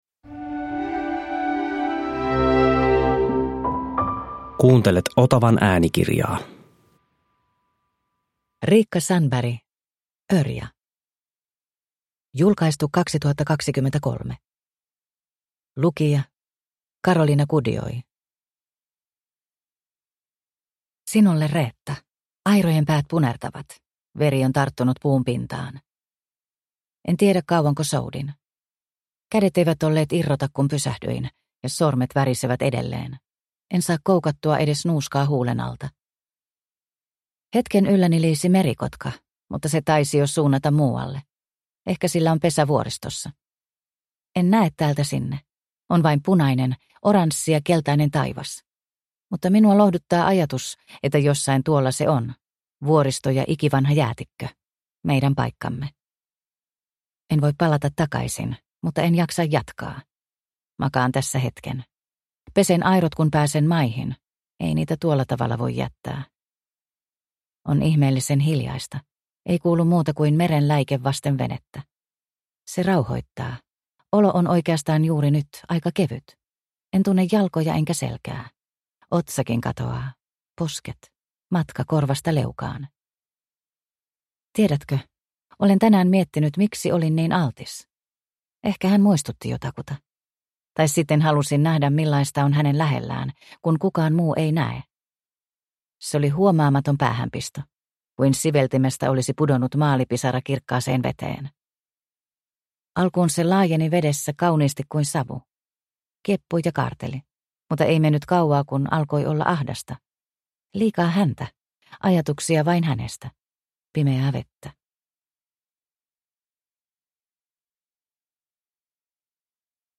Ørja – Ljudbok